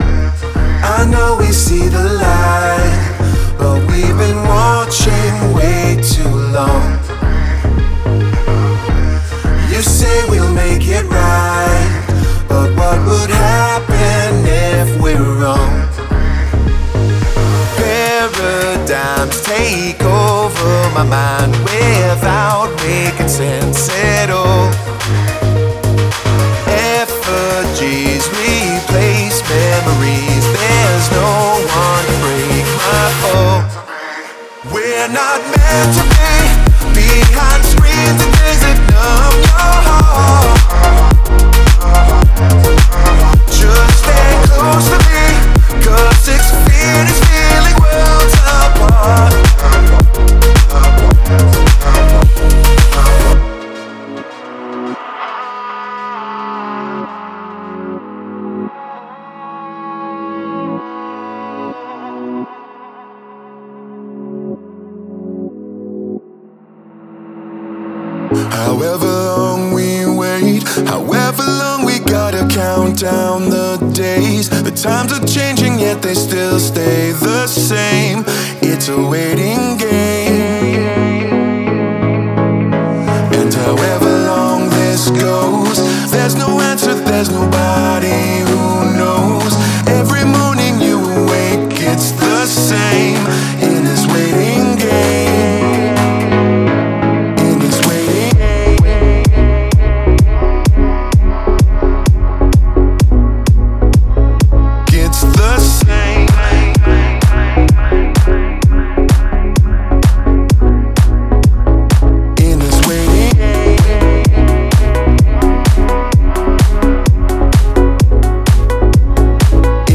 male edm vocals
– 32 Harmonies (Dry & Wet)
24-bit WAV Stereo
– Full Stack, Falsetto & Normal
MP3 DEMO